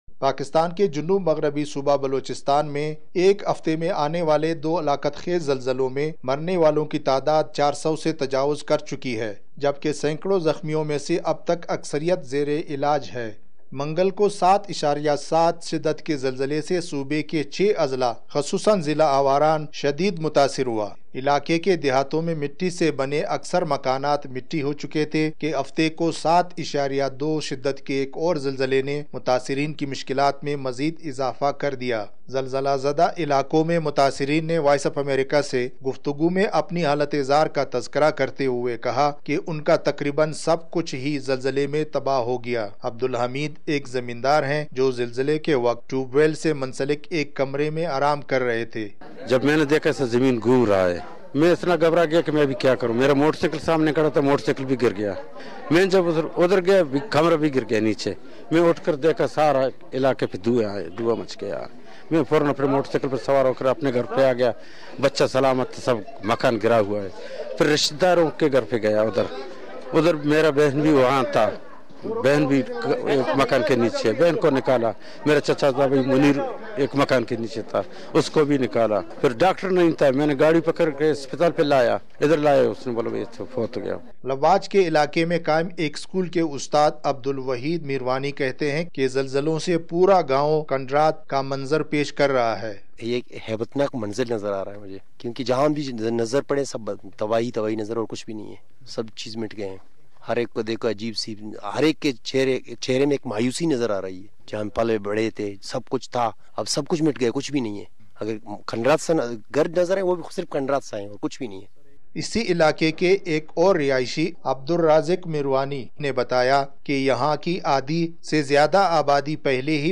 مفصل رپورٹ